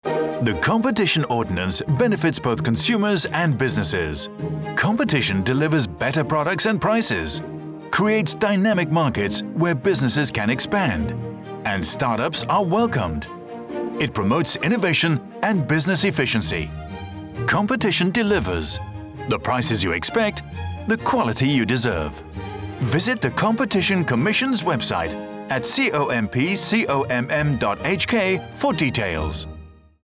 Radio Announcements